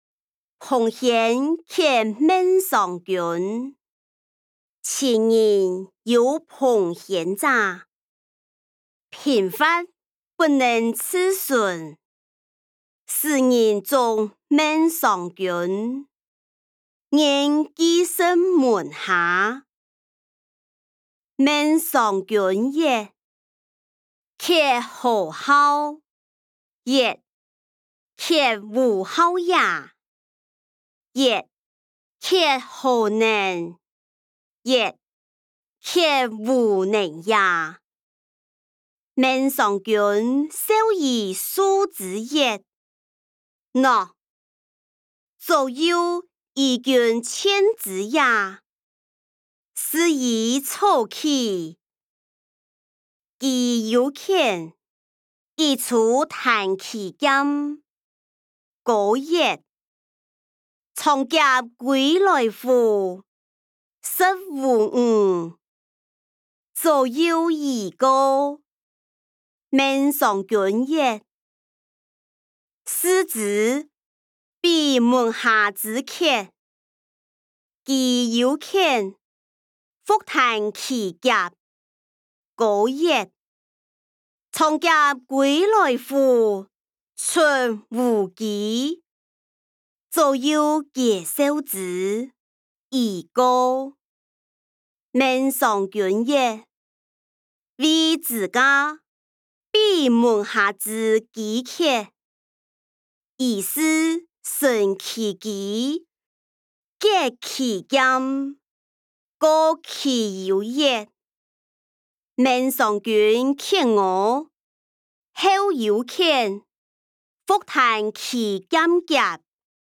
歷代散文-馮諼客孟嘗君音檔(四縣腔)